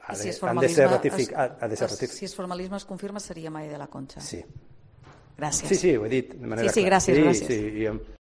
Corte del Informativo Mediodía